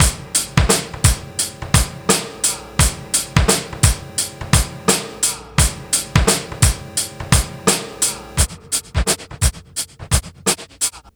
Index of /90_sSampleCDs/Best Service ProSamples vol.24 - Breakbeat [AKAI] 1CD/Partition A/TRIBEVIBE086